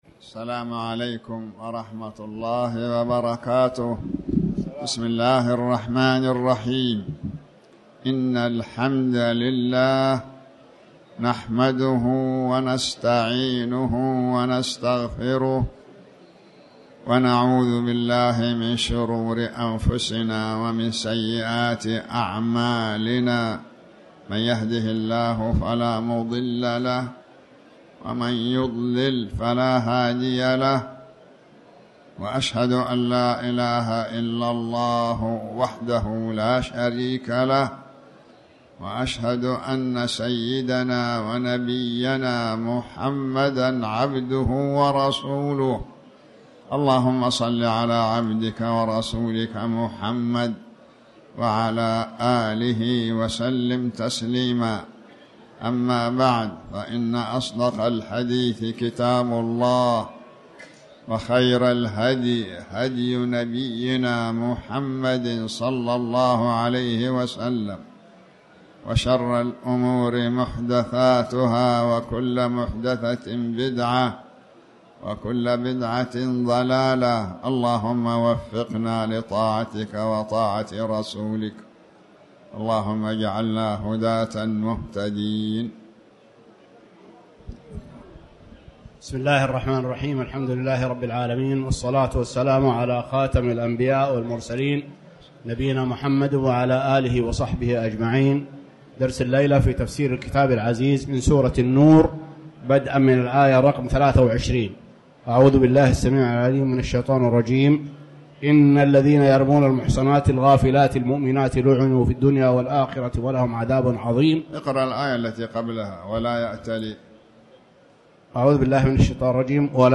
تاريخ النشر ٢٥ ربيع الأول ١٤٤٠ هـ المكان: المسجد الحرام الشيخ